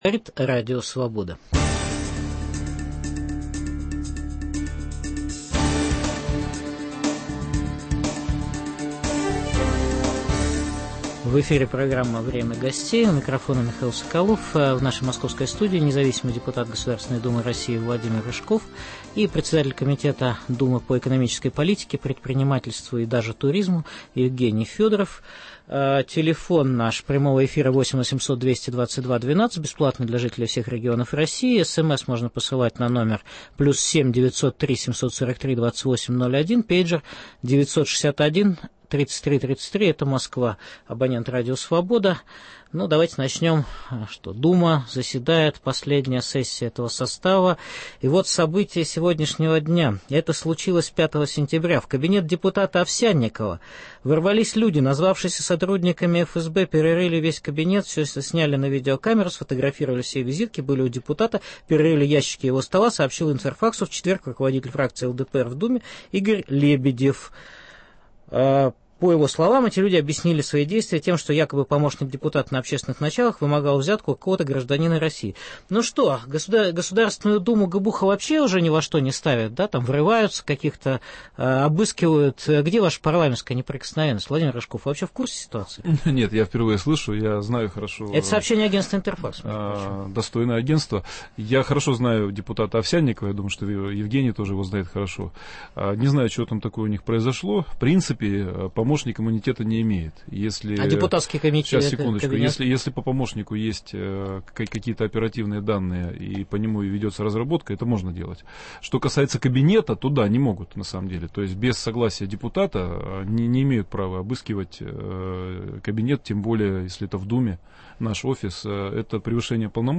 В программе выступят: независимый депутат Государственной Думы РФ Владимир Рыжков и председатель комитета по экономической политике, предпринимательству и туризму Евгений Федоров (фракция "Единая Россия").